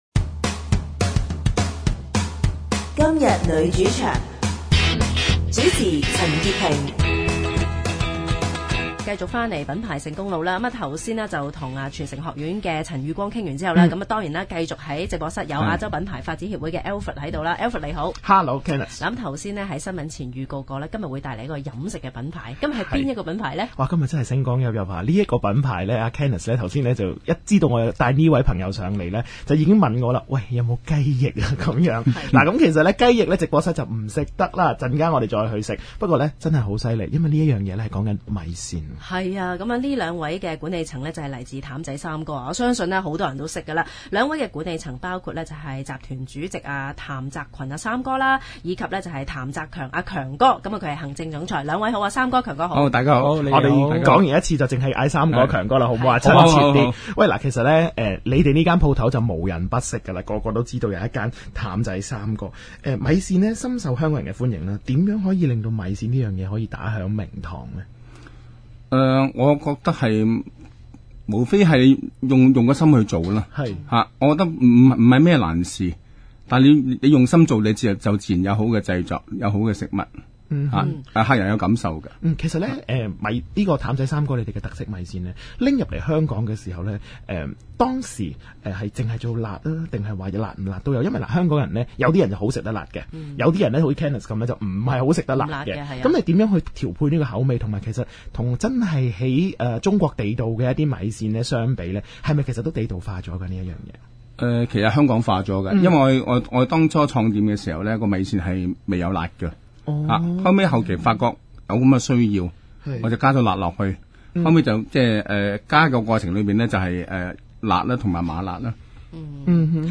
受訪嘉賓：